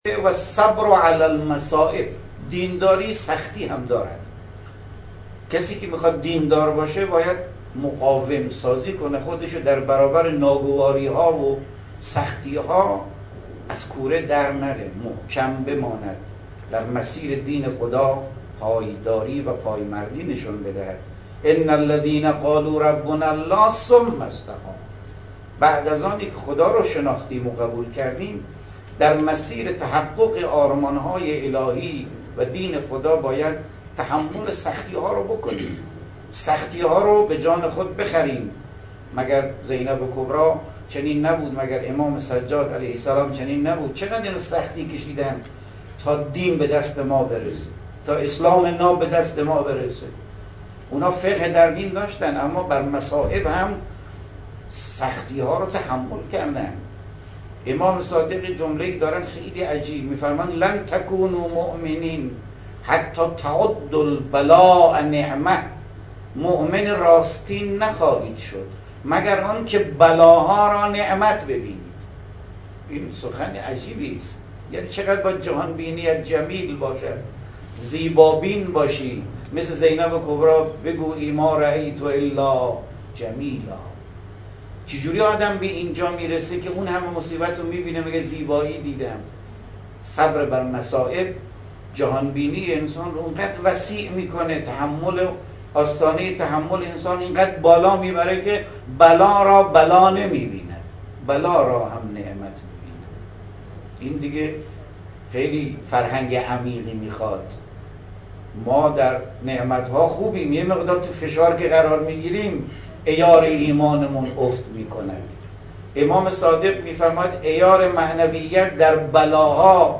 به گزارش خبرنگار خبرگزاری رسا در خراسان شمالی، حجت الاسلام والمسلمین ابوالقاسم یعقوبی، نماینده ولی فقیه در خراسان شمالی و امام جمعه بجنورد، امروز در نشست شورای هماهنگی گرامیداشت دهه کرامت استان، اظهار کرد: بنا به فرموده امام علی(ع) کسی طعم دین را می چشد که فهم عمیق دین، صبر بر مصیبت ها و تلاش برای معاش داشته باشد.